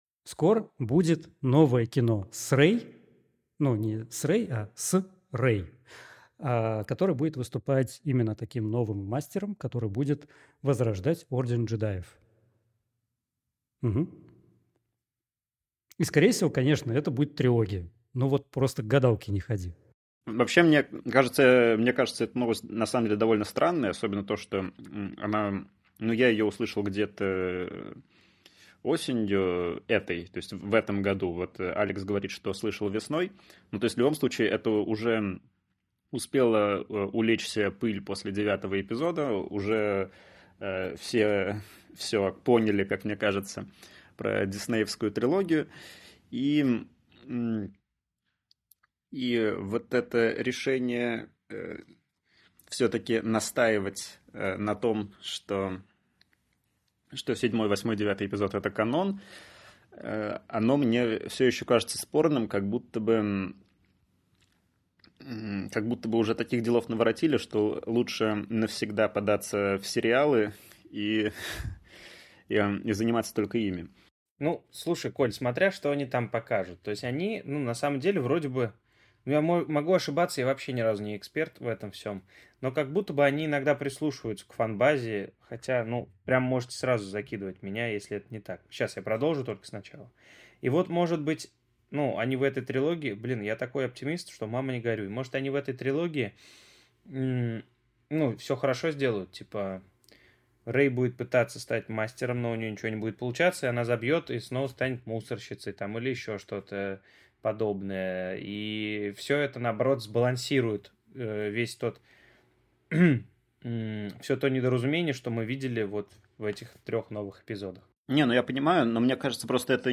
В последний раз, в этом году, мы собрались вокруг микрофонов, дабы окунуться в "Звёздные войны" и в последний раз поговорить про "Асоку", обещаем.